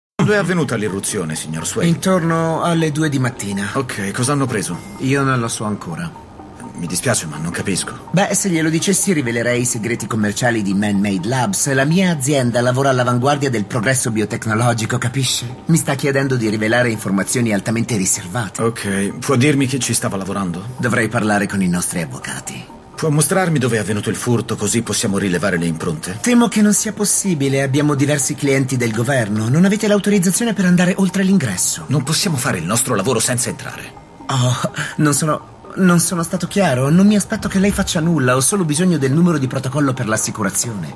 nel telefilm "The Rookie", in cui doppia Jay Paulson.